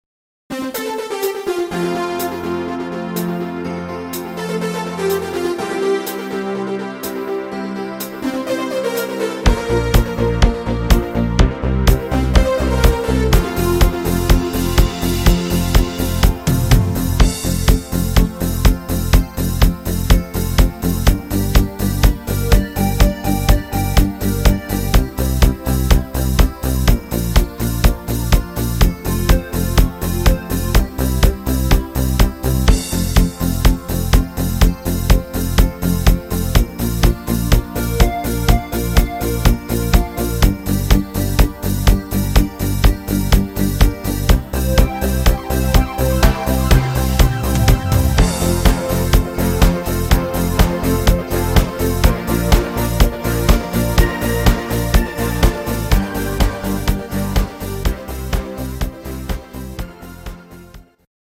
Partymix